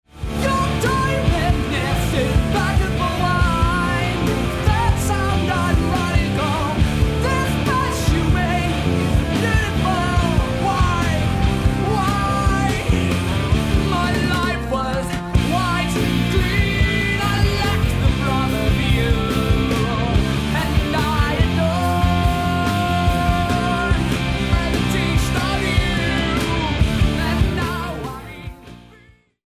Good songs formed in power chord guitar arrangements.....